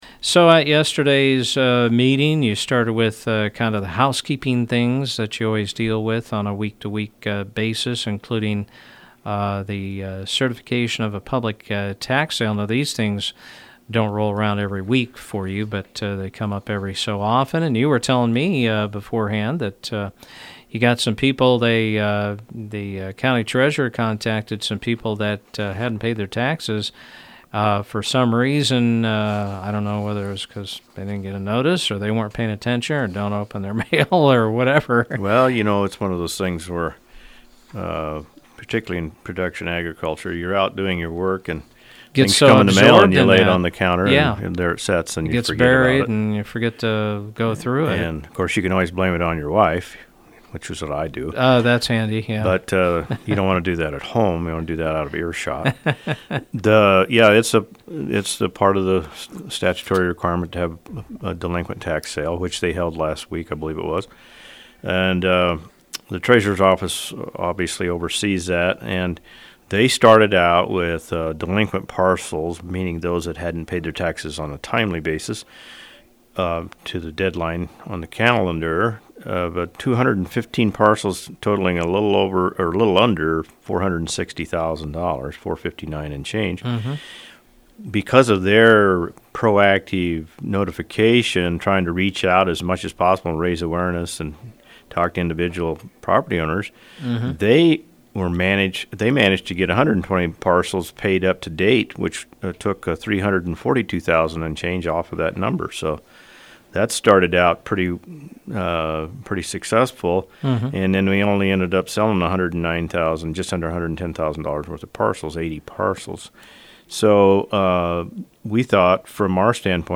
Chairman of the Lincoln Co Commissioners, Kent Weems, came on Mugs Tuesday to talk about their Monday meeting: